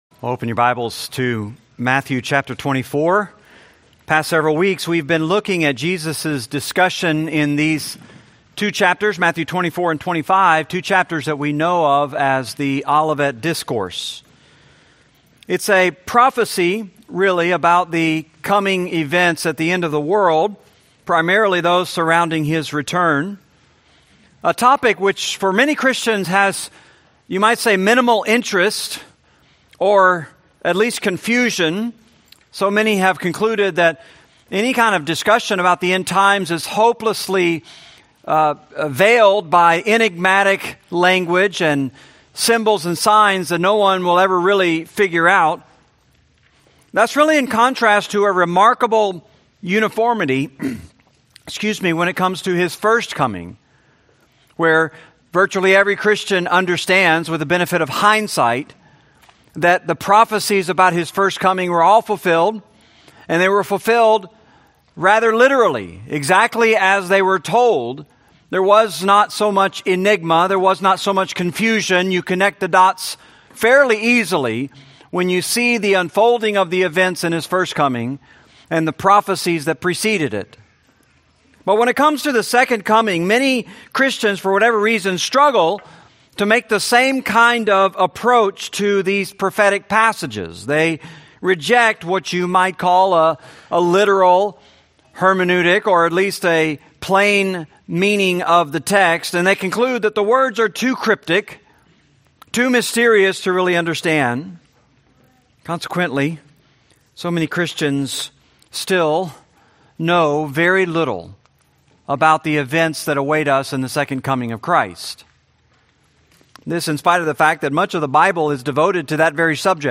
Series: Matthew, Sunday Sermons